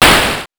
8 bits Elements / explosion
explosion_19.wav